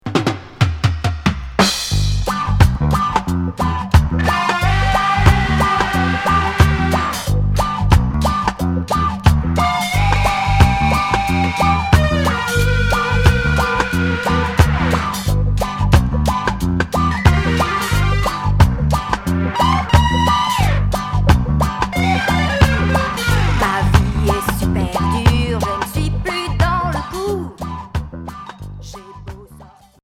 Rock reggae new wave